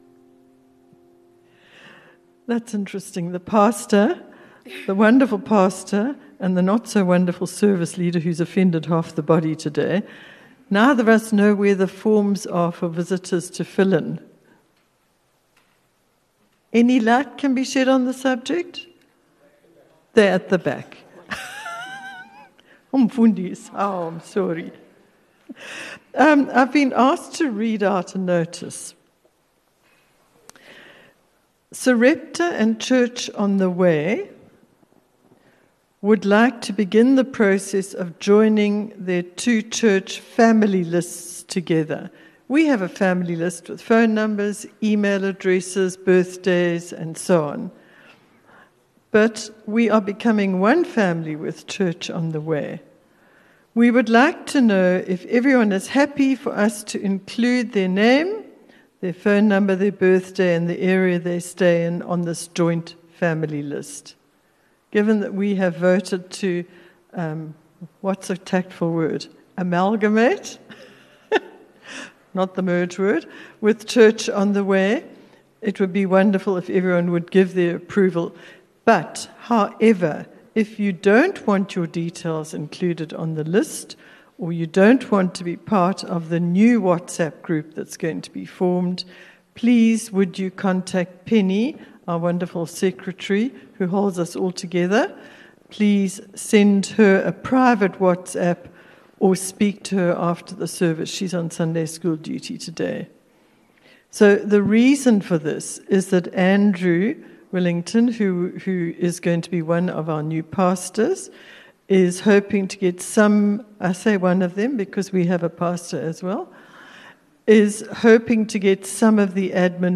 View Promo Continue JacPod Install Upper Highway Vineyard Sunday messages 18 Aug Preach - 18 Aug 2024 26 MIN Download (12.5 MB) God is my comforter ENGLISH SOUTH AFRICA 00:00 Playback speed Skip backwards 15 seconds